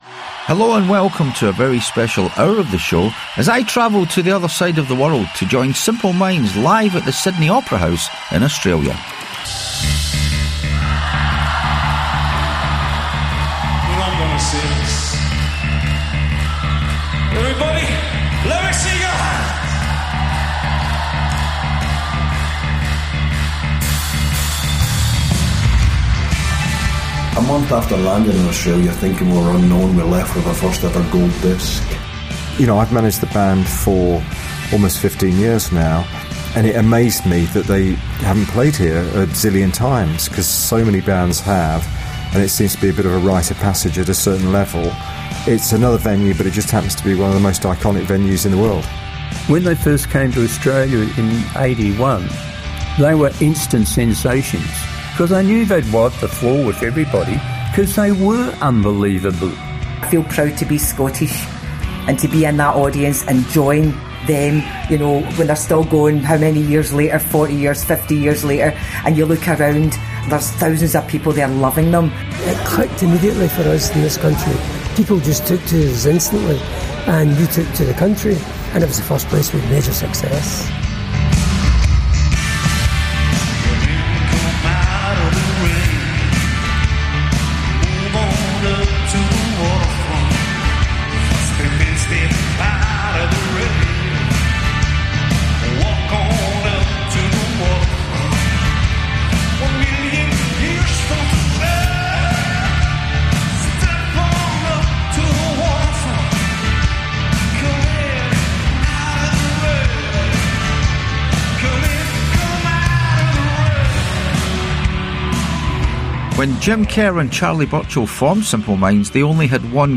anticipating the middle hour of the show which would be the airing of the documentary of Simple Minds tour of Australia last month.